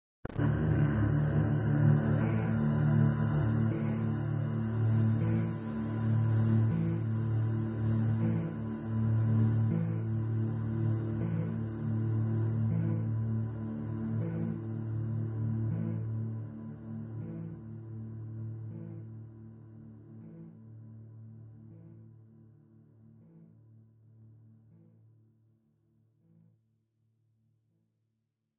语音无人机
描述：奇怪的声音效果
Tag: 雄蜂 人声 语音